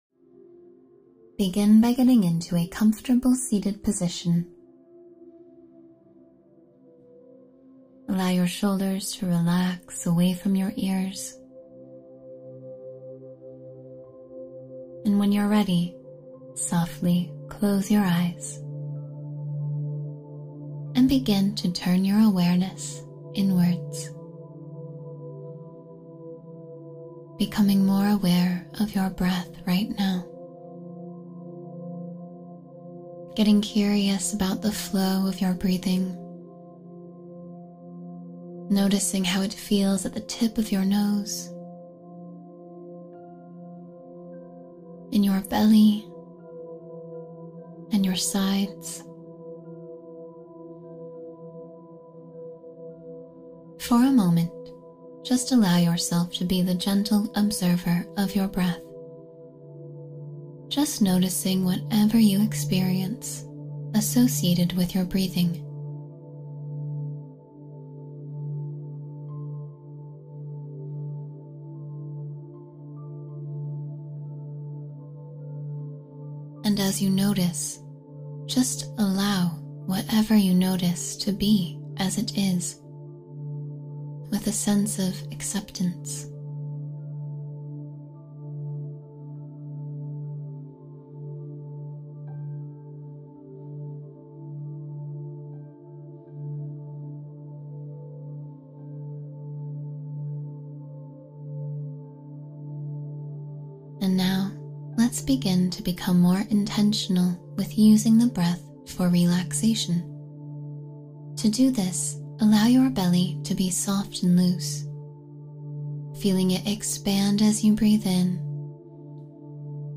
Stop Overthinking and Return to Calm — Guided Meditation for Mental Clarity